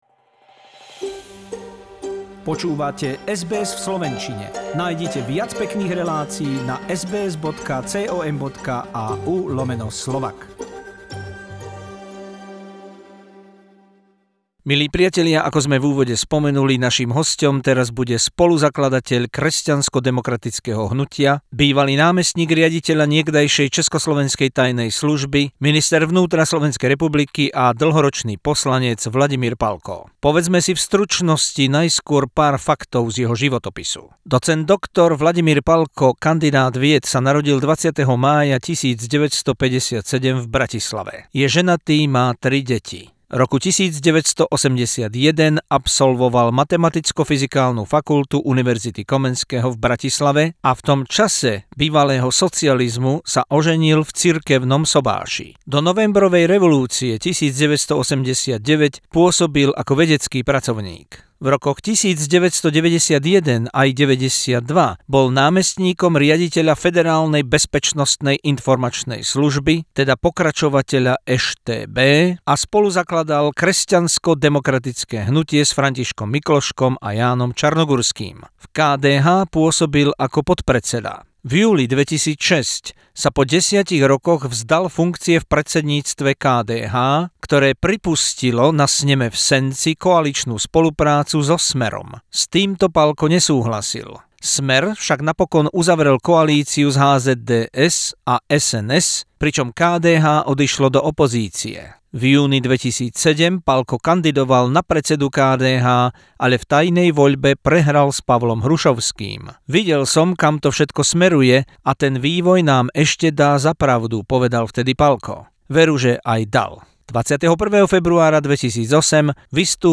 SBS exclusive interview with Vladimir Palko, co-founder of KDH, former vice-director of Czecho-Slovak secret service, minister of home affairs, Slovak MP.